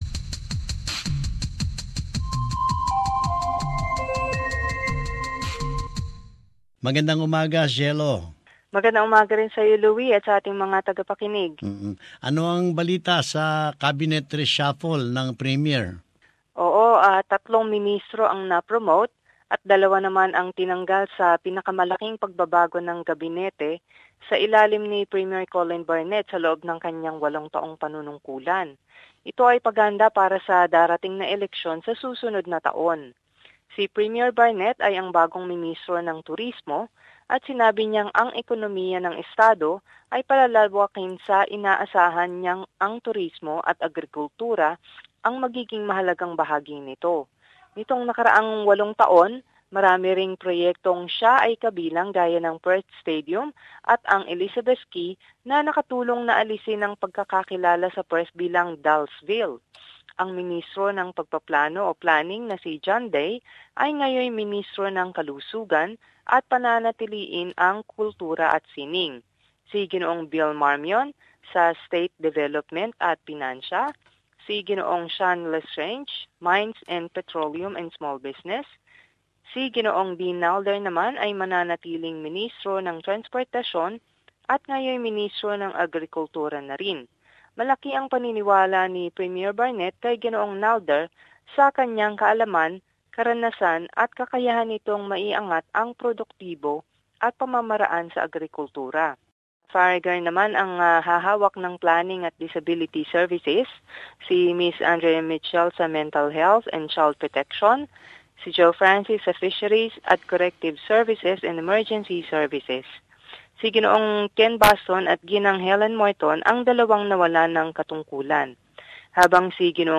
Perth Report: Summary of latest news in the state